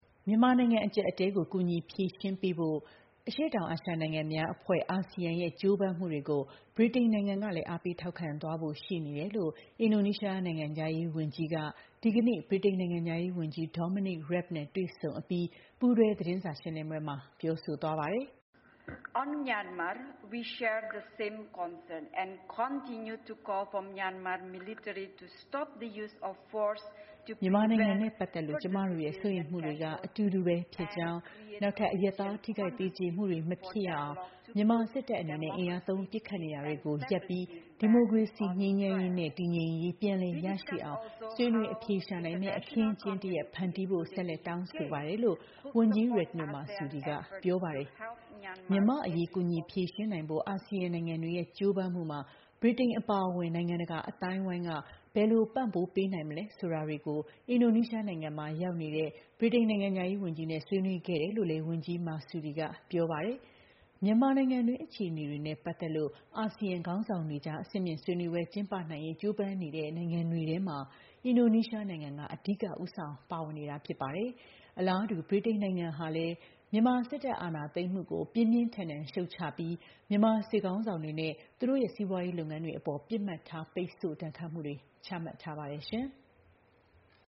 မြန်မာနိုင်ငံအကျပ်အတည်းကို ကူညီဖြေရှင်းဖို့ အရှေ့တောင်အာရှနိုင်ငံများအဖွဲ့ (ASEAN) ရဲ့ ကြိုးပမ်းမှုတွေကို ဗြိတိန် ကလည်း ထောက်ခံသွားဖို့ရှိနေတယ်လို့ အင်ဒိုနီးရှားနိုင်ငံခြားရေးဝန်ကြီးက ဒီကနေ့ ဗြိတိန်နိုင်ငံခြားရေးဝန်ကြီး Dominic Raab နဲ့ တွေ့ဆုံအပြီး ပူးတွဲ သတင်းစာရှင်းလင်းပွဲမှာ ပြောဆိုသွားပါတယ်။